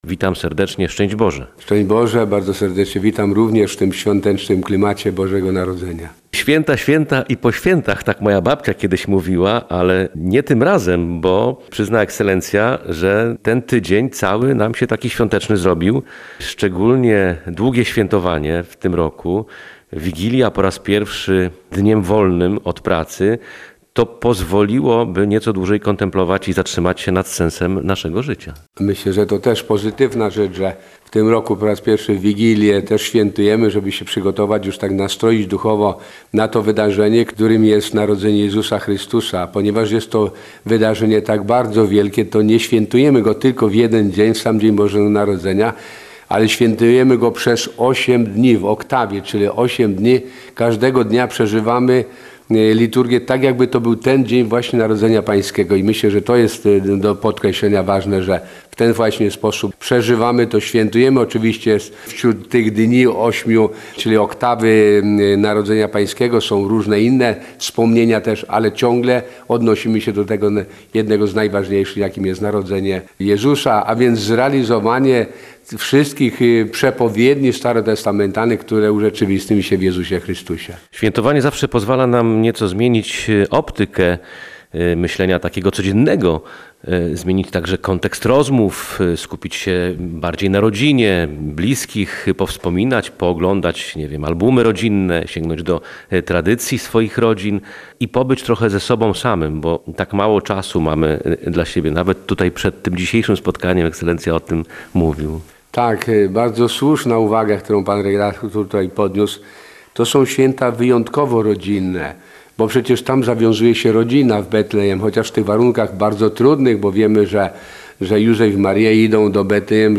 Kto by o nim chciał myśleć? – pytał w Radiu Gdańsk metropolita gdański arcybiskup Tadeusz Wojda.